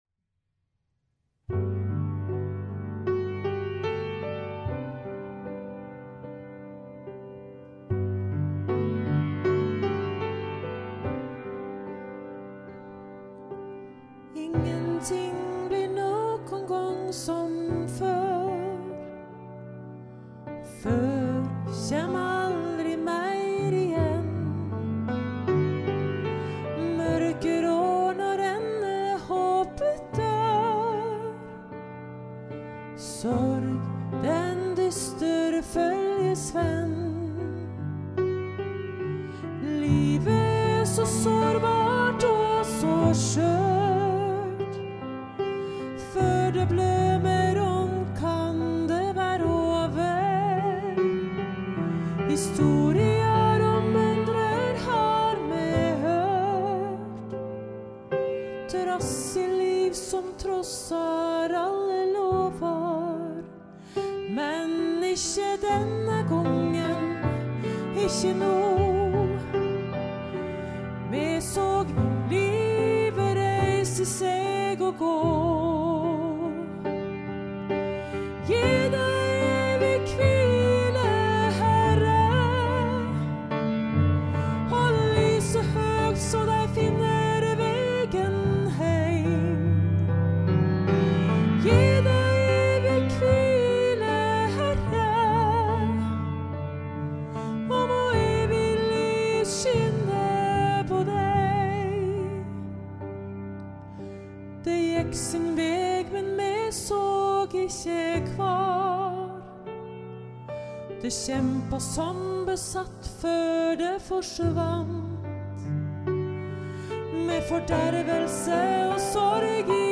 Musikk til begravelse - våre solister
messo-sopran
Hun har en stemme med nær og rolig klang og kan tilpasse seg flere ulike sjangere.